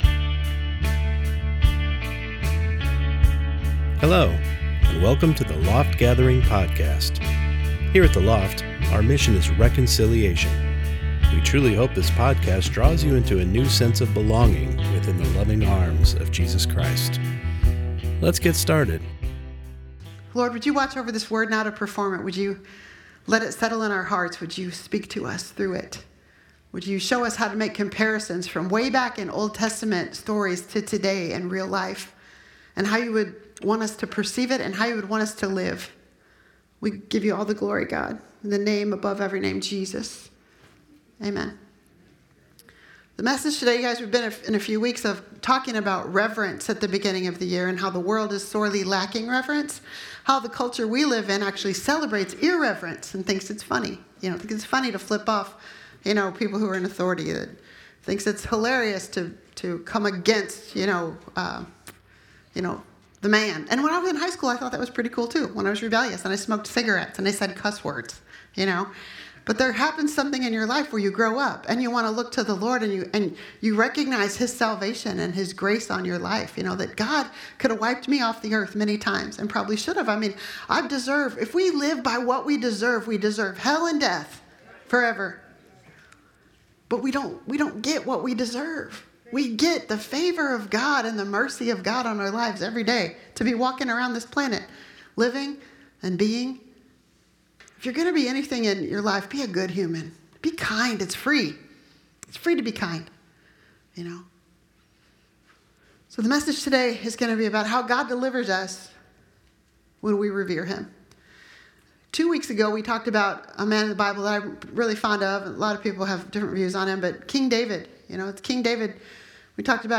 Sunday Morning Service Service: Sunday Morning Service God delivers us when we revere Him.